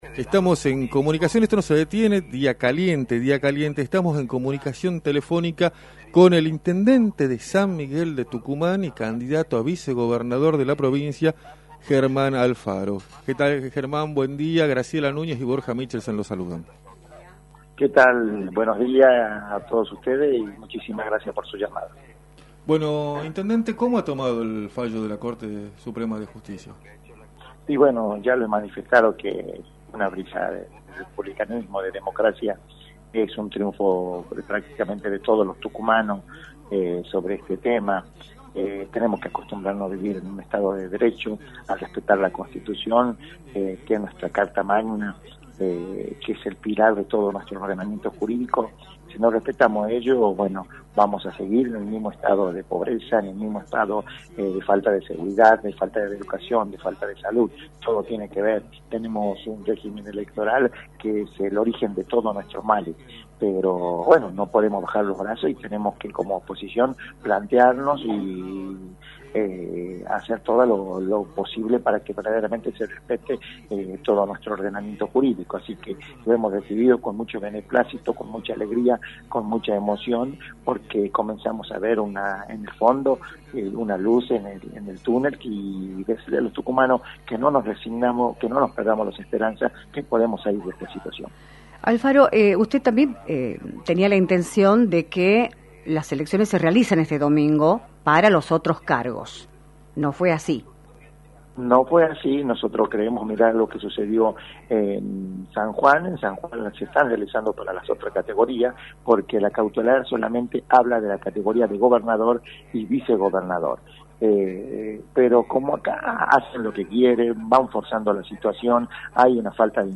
Germán Alfaro, Intendente de San Miguel de Tucumán y candidato a Vicegobernador por Juntos por el Cambio, analizó en “Libertad de Expresión” por la 106.9, la decisión de la Corte Suprema de Justicia de suspender las elecciones del 14 de mayo hasta que se resuelva la candidatura de Juan Manzur.